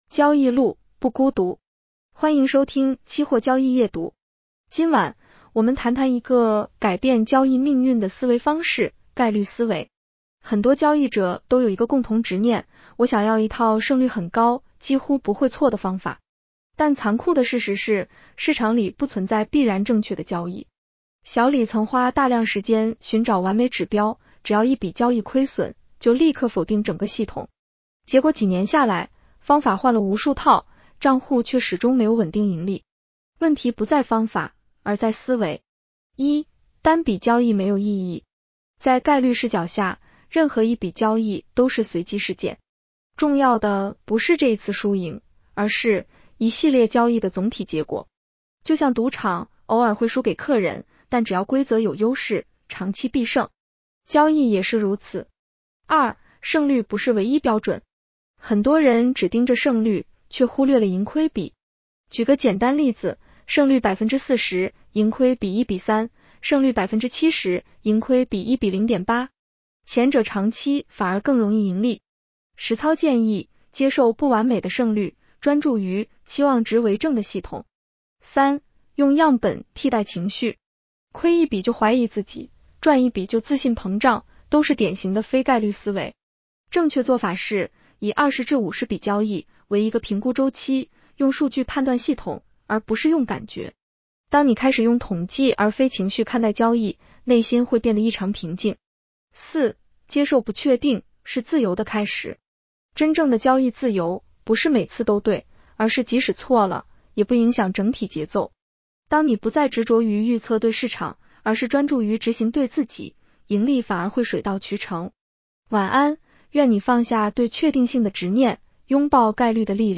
女声普通话版 下载mp3
（AI生成）